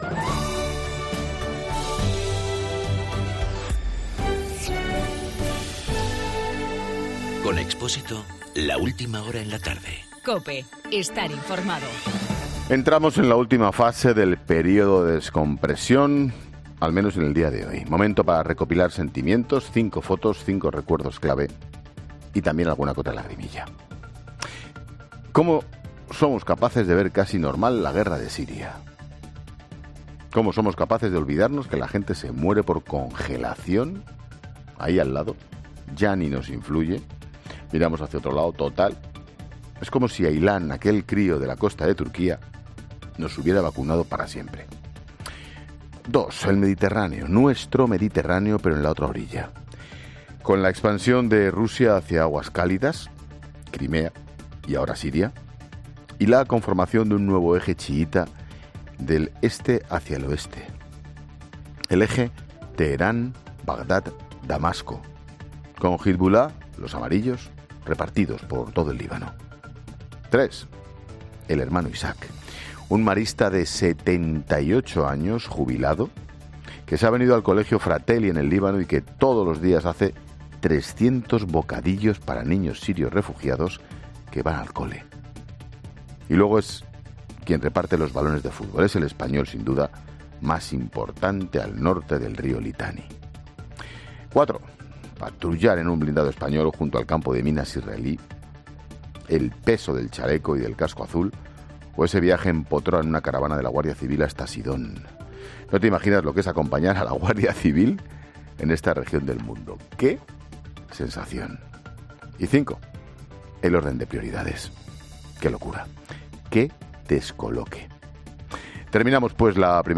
Monólogo de Expósito
El comentario de Ángel Expósito después de volver de Líbano.